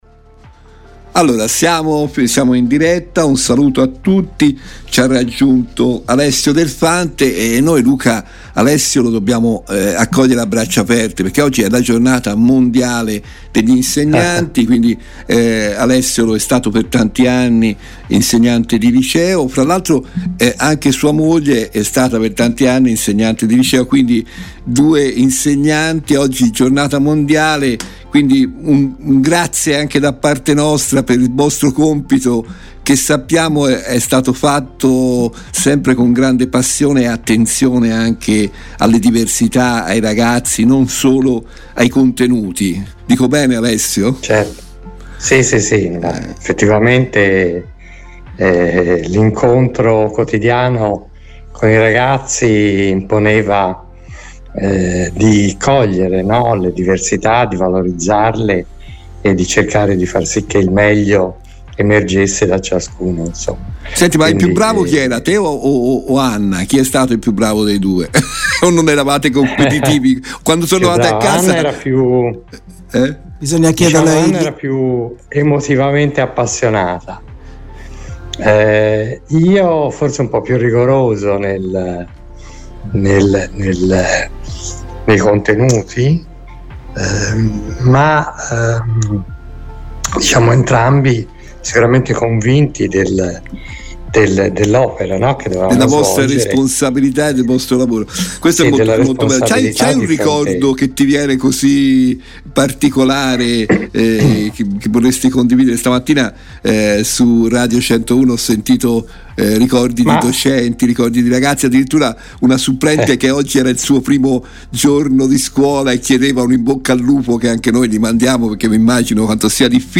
Nel corso della diretta RVS del 05 ottobre 2023